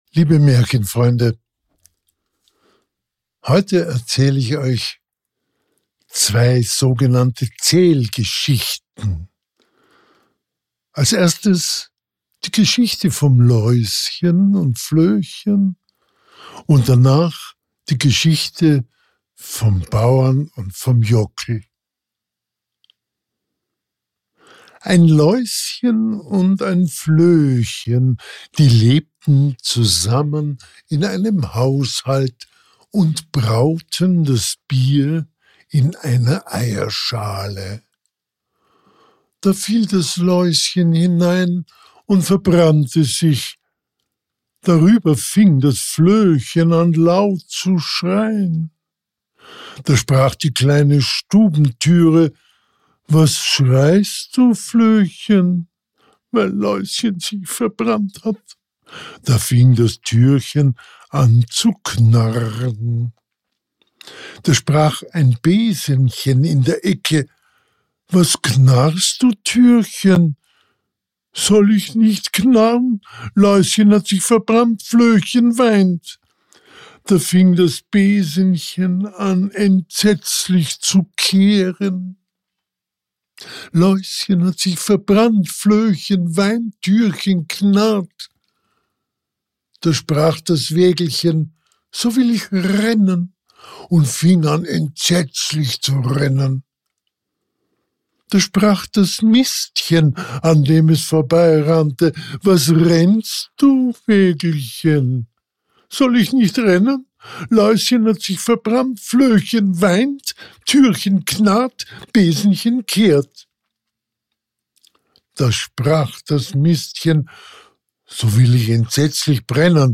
Heute erzähle ich Euch 2 kurze Geschichten: